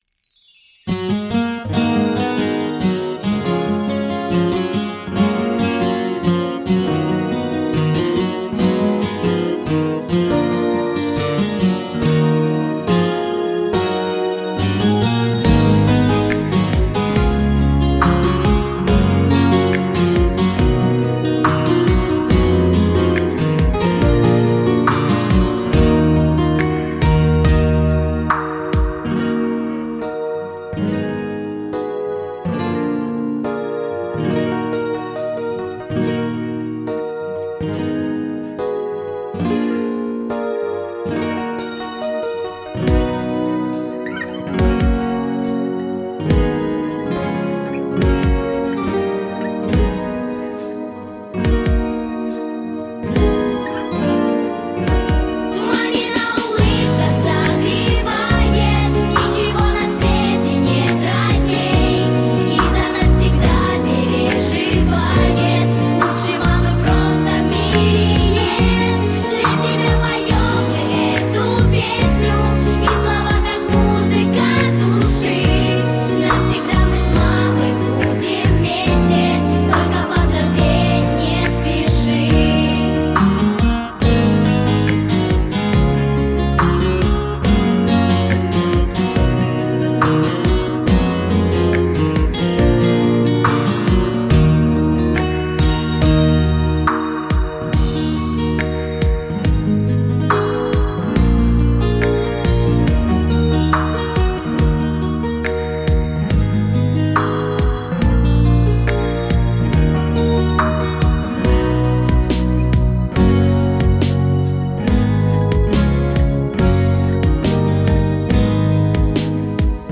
Главная / Песни для детей / Песни про маму
Слушать или скачать минус